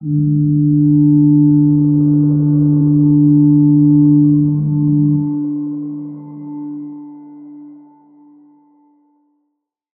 G_Crystal-D4-pp.wav